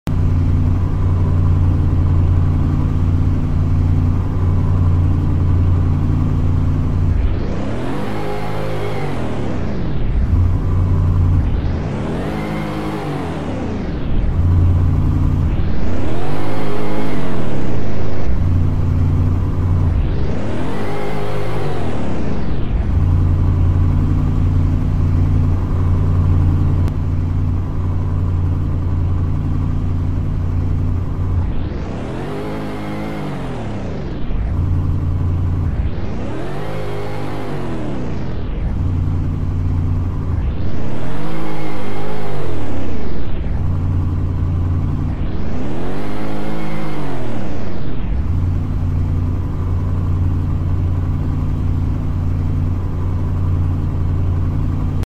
2025 Defender 110 V8 sound, sound effects free download
I deleted the first one because it kinda sound quiet with the audio, but I fixed the audio on this one so it should be good…